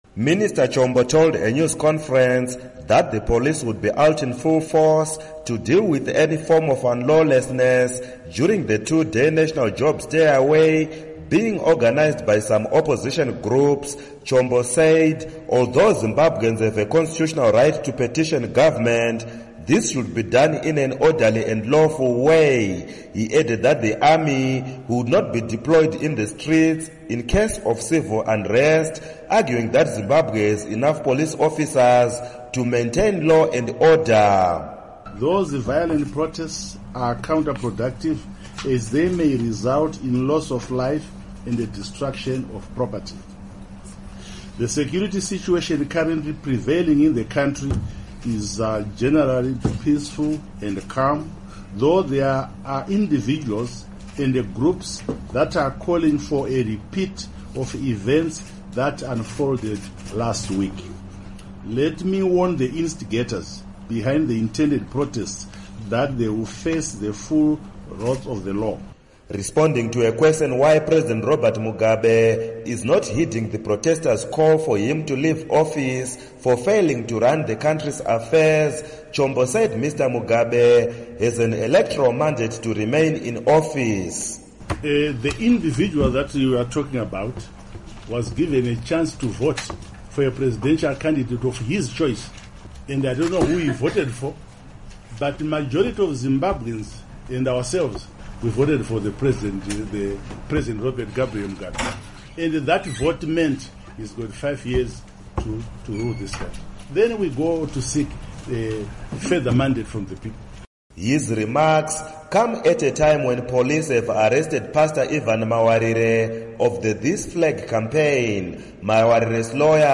Report On Shutdown